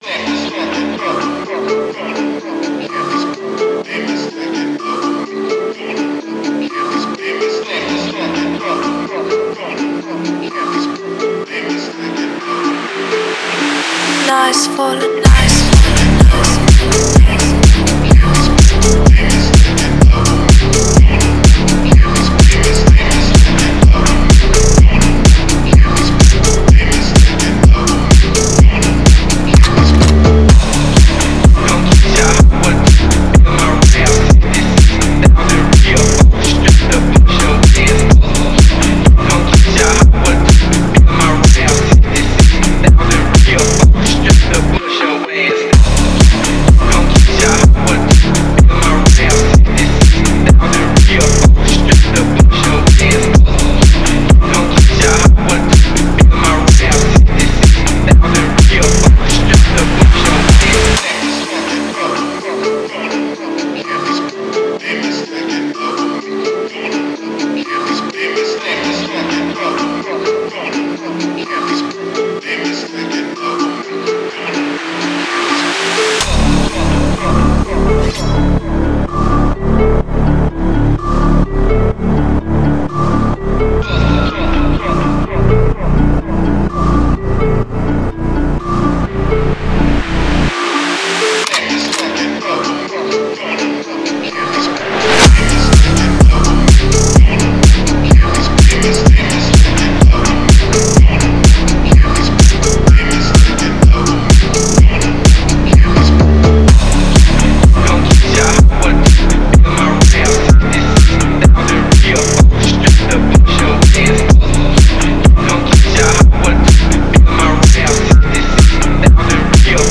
Категория: Фонк музыка
Phonk